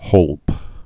(hōlp)